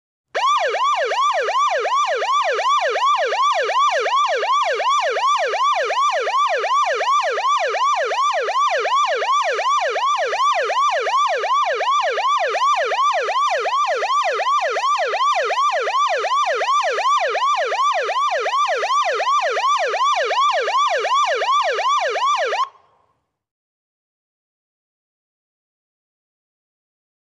Police Whooper Siren Close, On Steady And Off At Tail.